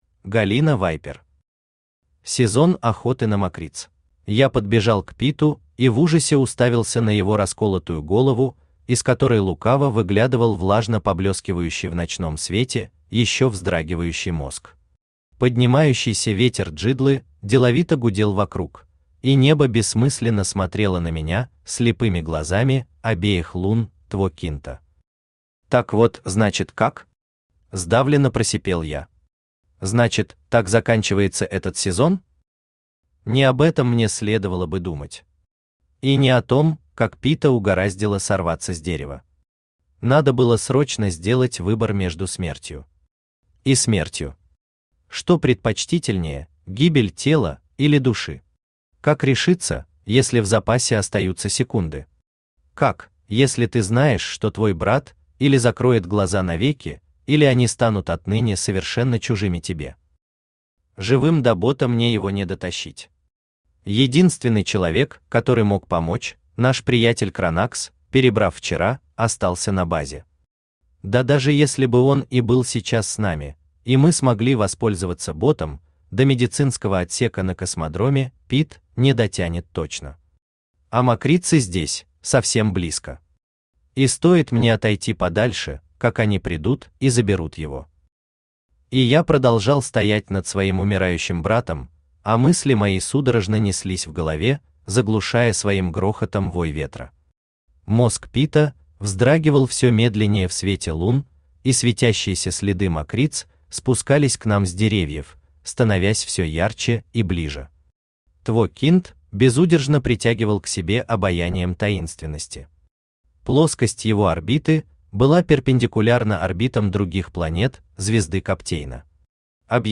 Аудиокнига Сезон охоты на мокриц | Библиотека аудиокниг
Aудиокнига Сезон охоты на мокриц Автор Галина Вайпер Читает аудиокнигу Авточтец ЛитРес.